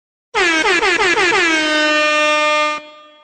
MLG SOUND EFFECT AIR HORNS!